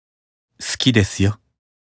Chat Voice Files